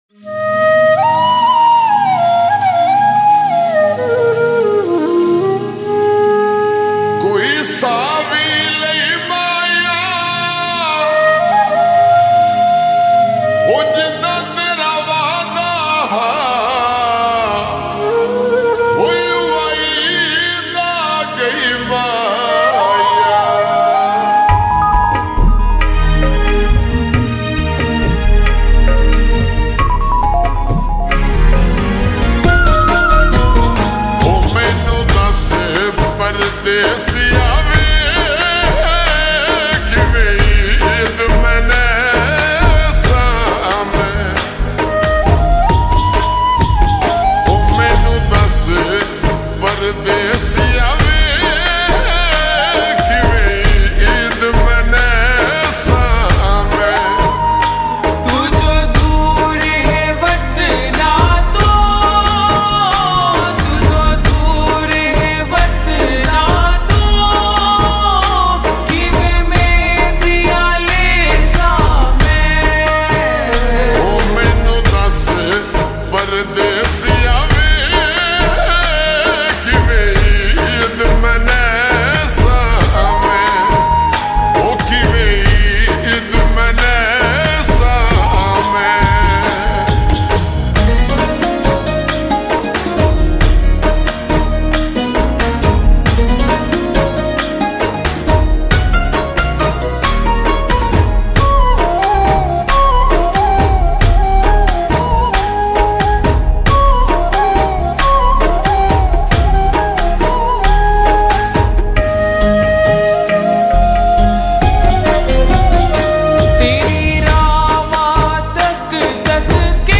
𝐒𝐚𝐫𝐚𝐢𝐤𝐢 𝐬𝐨𝐧𝐠
𝐏𝐮𝐧𝐣𝐚𝐛𝐢 𝐬𝐨𝐧𝐠